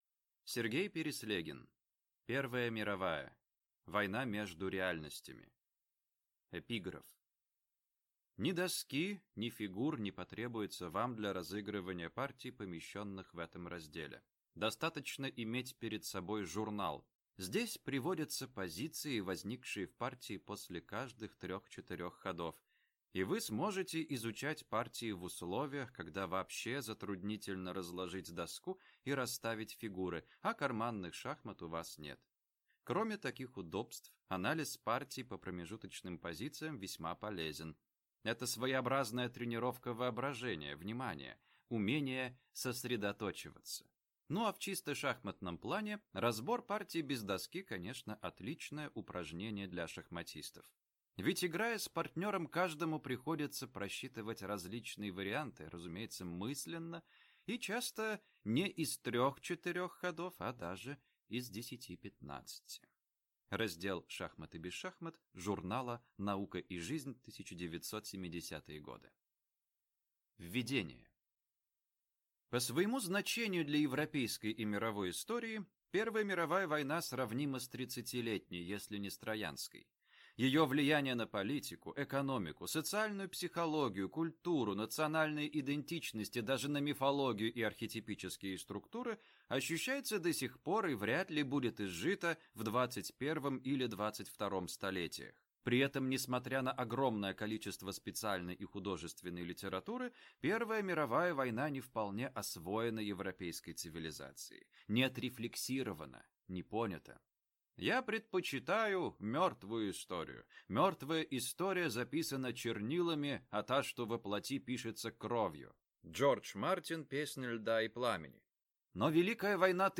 Аудиокнига Первая Мировая. Война между Реальностями | Библиотека аудиокниг